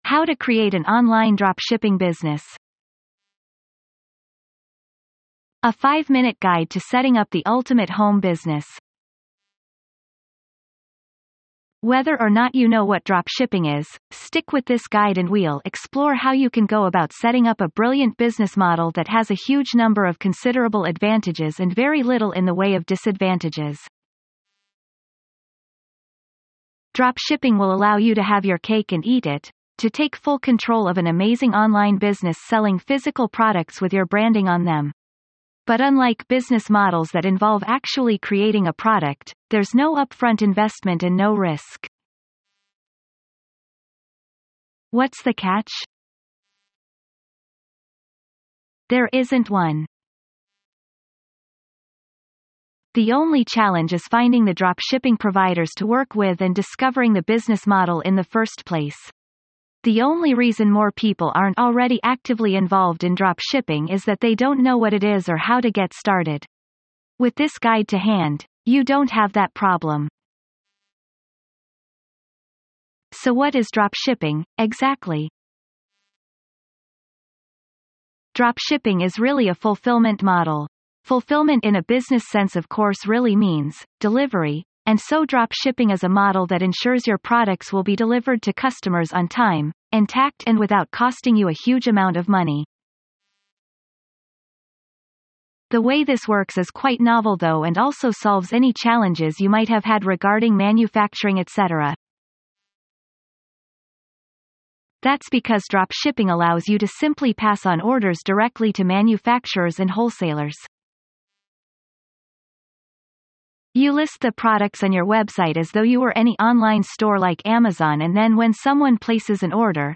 Includes an AI-narrated audio session and a matching eBook.